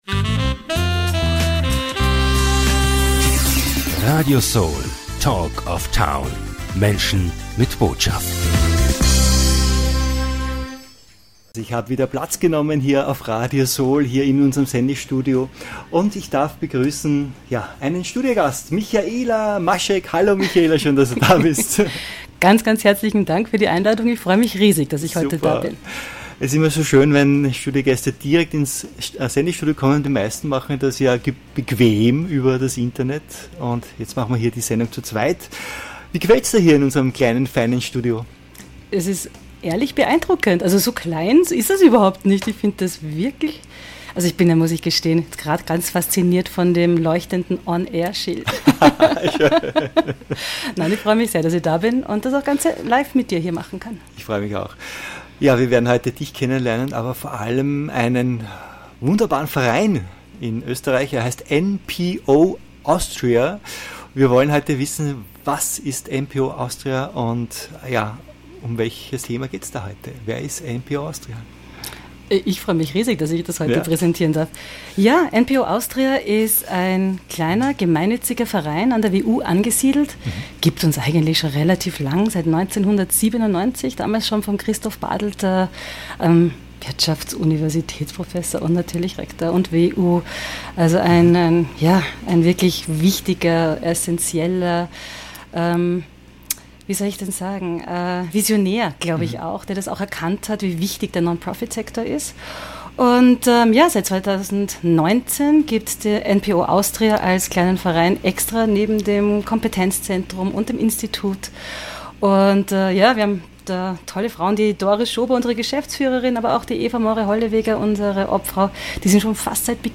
NPO´s On Air ~ Radio SOL Talk of Town - Menschen mit Botschaft Podcast